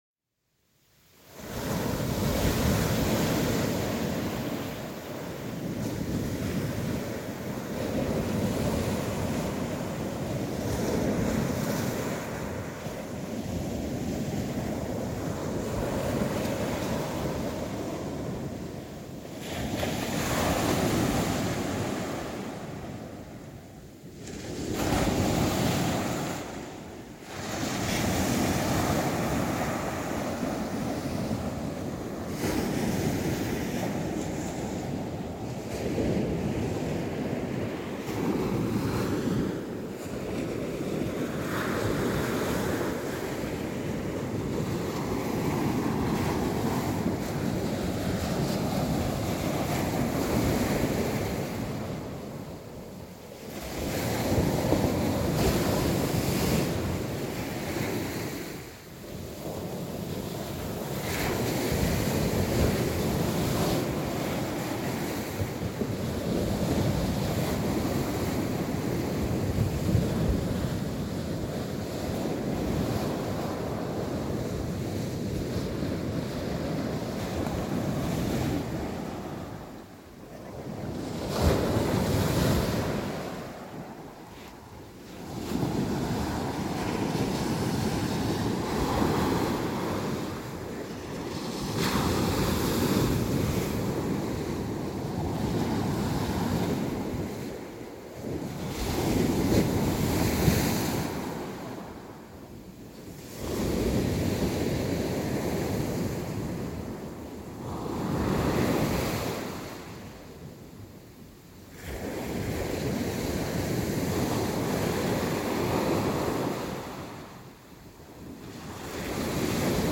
Lepší usínání - uspávací zvukové kulisy pro děti
Vyzkoušené monotónní zvuky, které pomáhají usnout!
Záznam obsahuje pět různých zvukových stop – pět hodin pro lepší usínání.
1. Moře
2. Jízda autem
3. Pračka